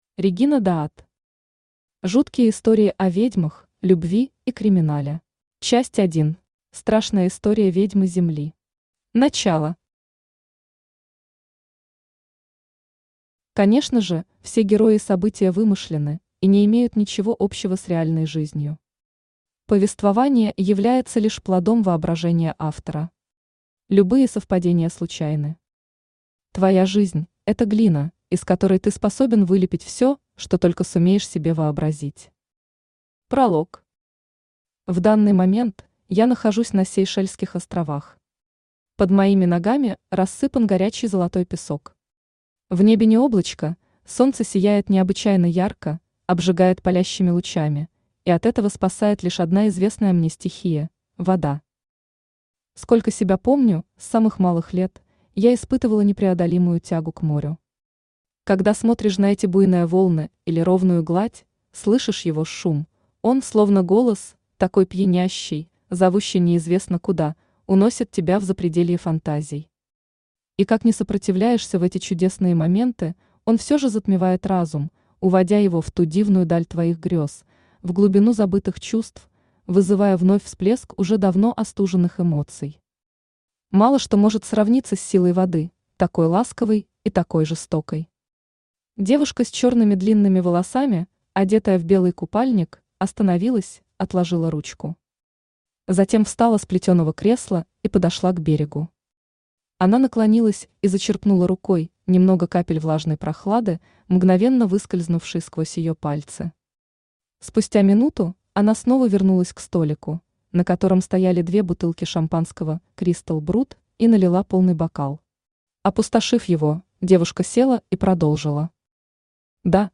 Аудиокнига Жуткие истории о ведьмах, любви и криминале | Библиотека аудиокниг
Aудиокнига Жуткие истории о ведьмах, любви и криминале Автор Регина Даат Читает аудиокнигу Авточтец ЛитРес.